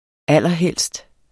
Udtale [ ˈalˀʌˈhεlsd ]